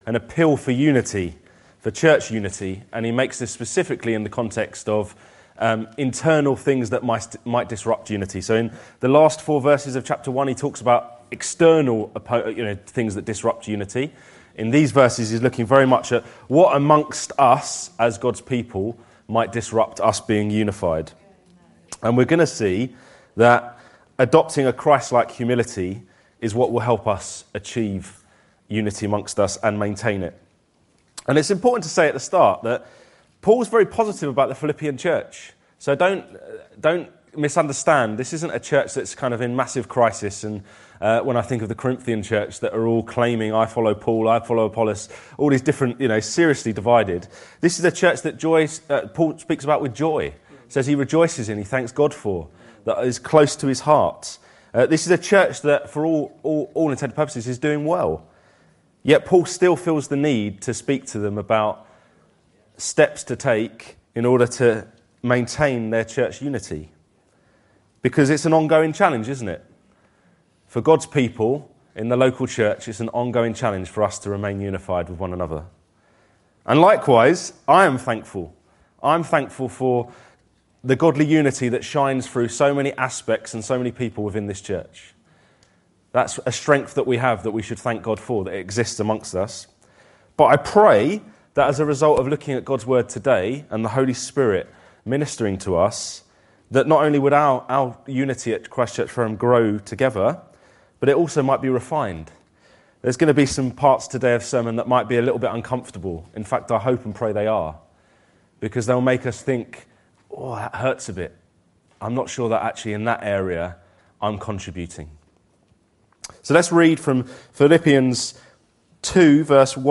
This sermon reflects on Paul’s call to the Philippian churches to remain united even when faced with internal disruptions.